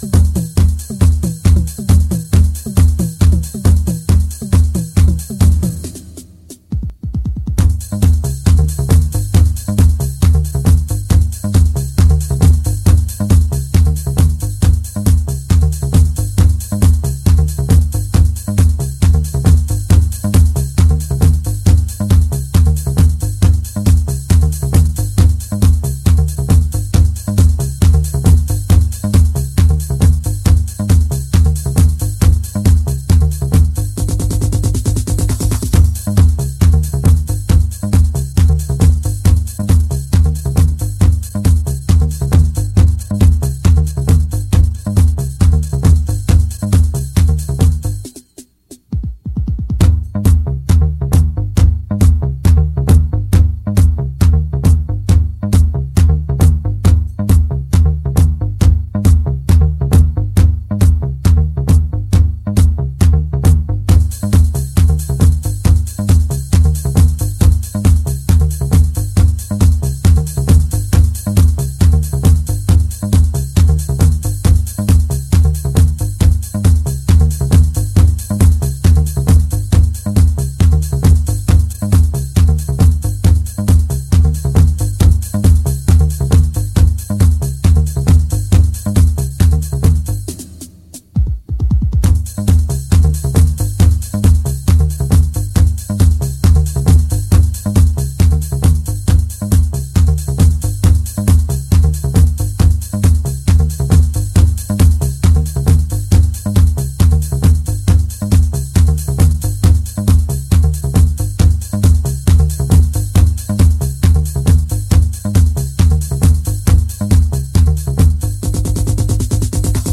unreleased 90's grooves